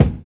LO FI 8 BD.wav